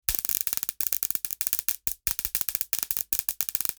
Electrical-zaps-electricity-sparks.mp3